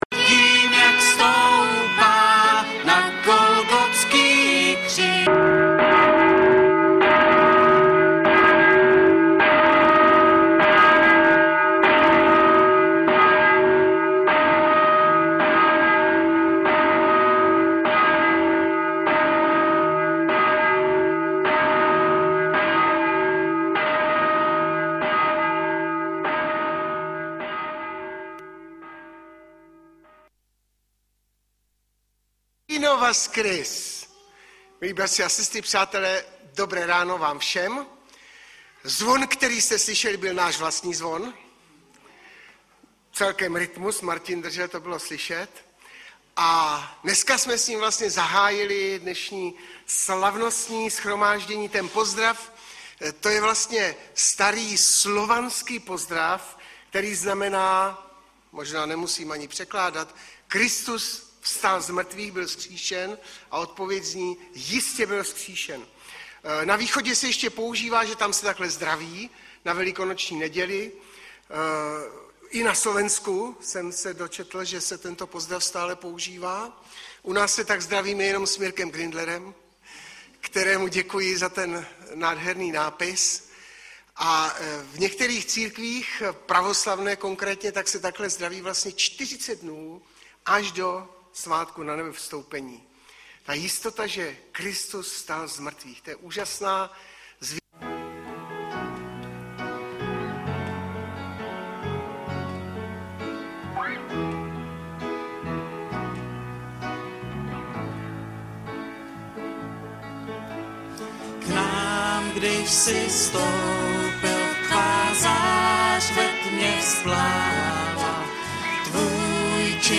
Webové stránky Sboru Bratrské jednoty v Litoměřicích.
Audiozáznam kázání